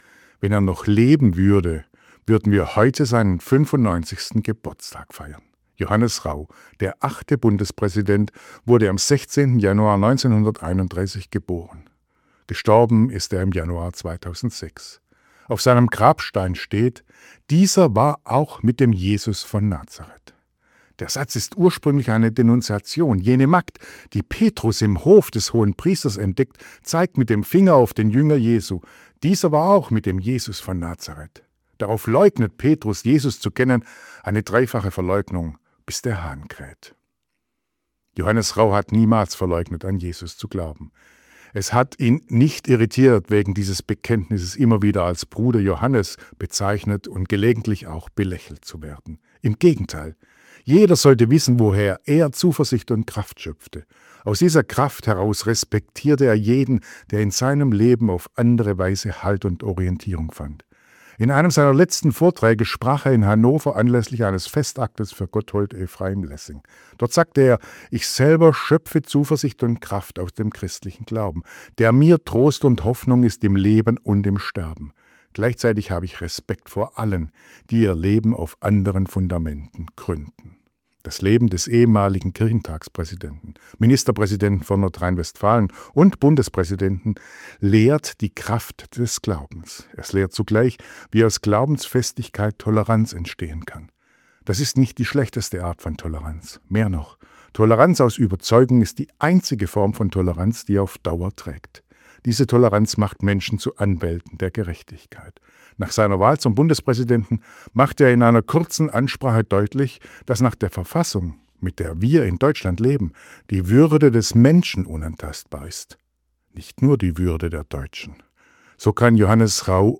Radioandacht vom 16. Januar